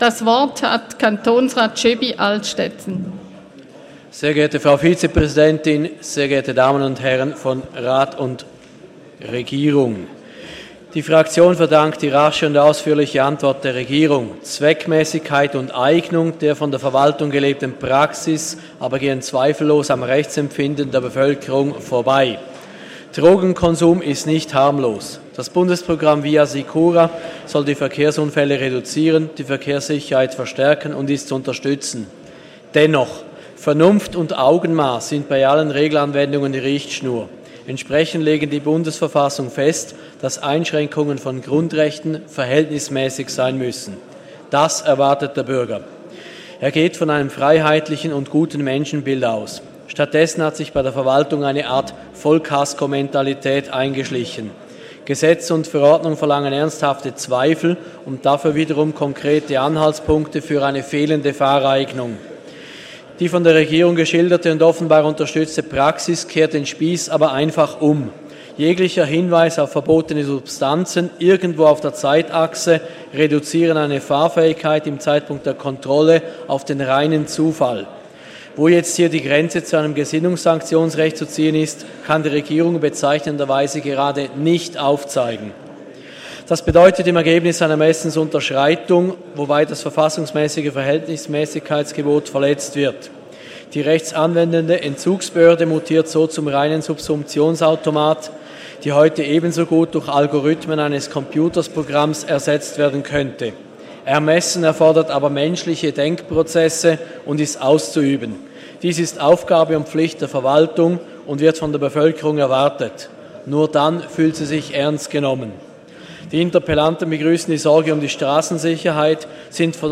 20.2.2018Wortmeldung
Session des Kantonsrates vom 19. und 20. Februar 2018